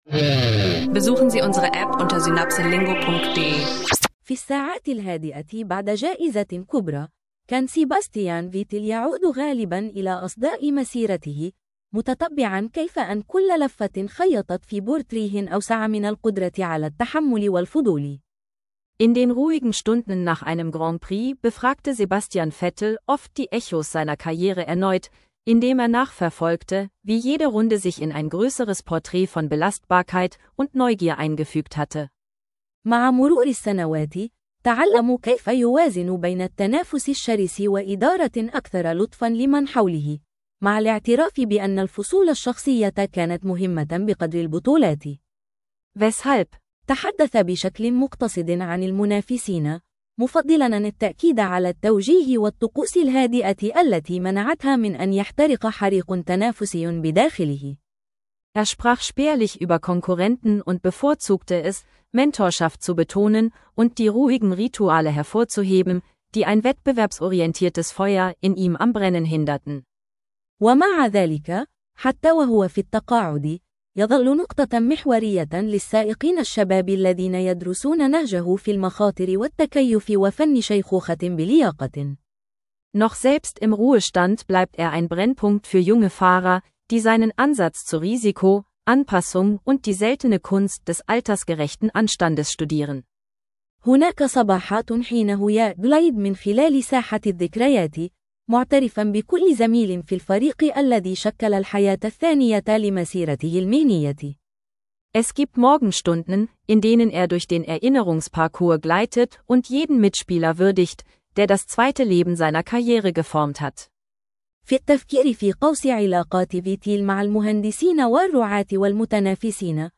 Ein KI-unterstützter Arabisch Lern-Podcast: Reflexion über Karriere, Beziehungen und Nachhaltigkeit im Sport – perfekt für Anfänger und Fortgeschrittene.